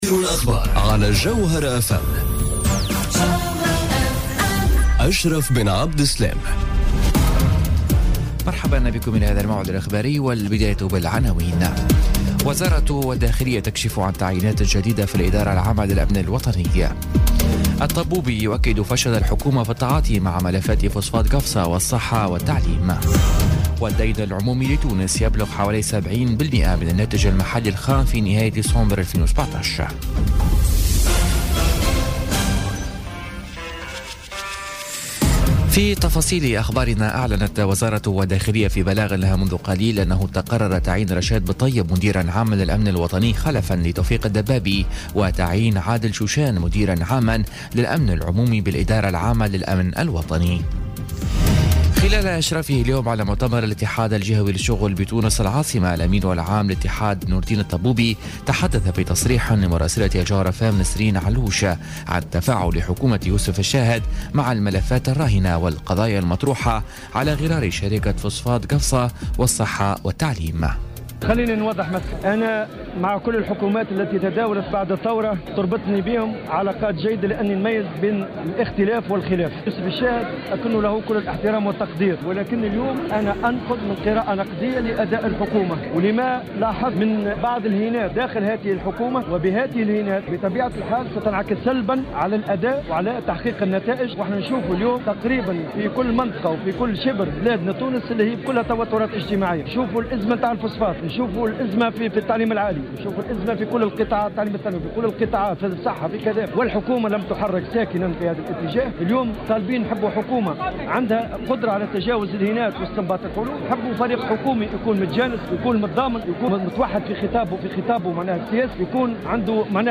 نشرة أخبار السابعة مساءً ليوم السبت 3 مارس 2018